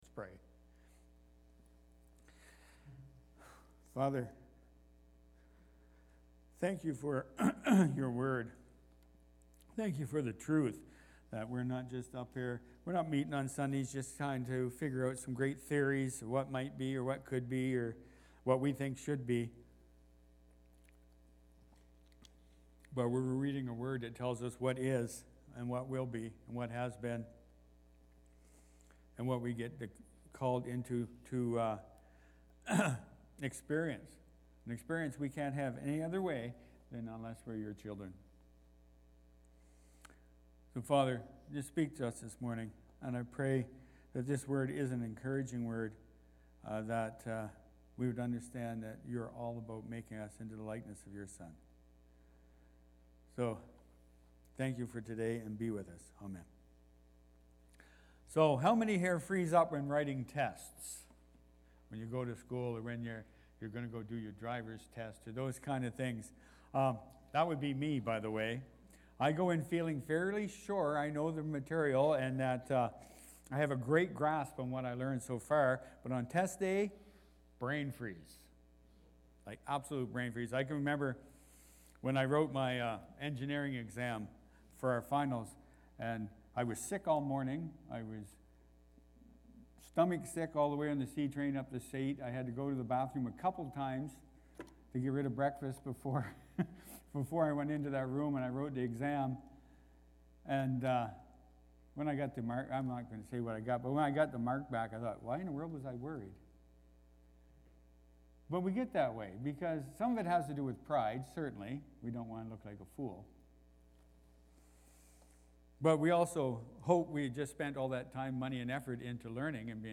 Surprise-Test-Sermon-Audio.mp3